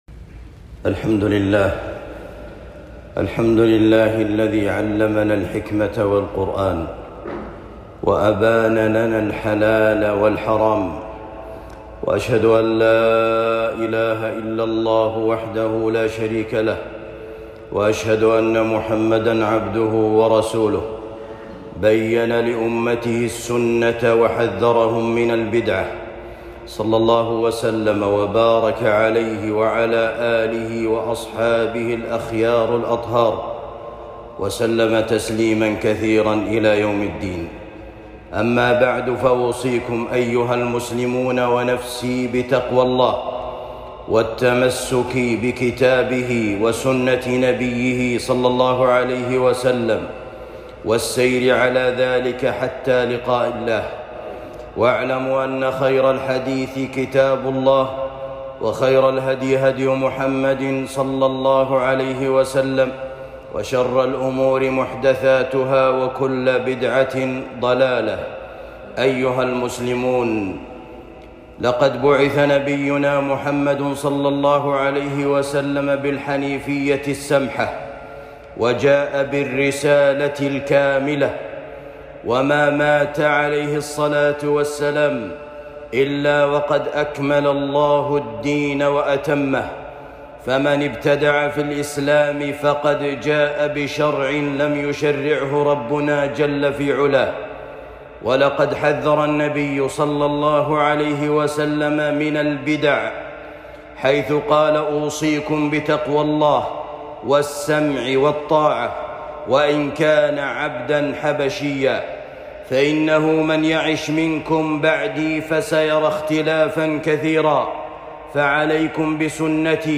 التحذير من محدثات نهاية العام خطبة جمعة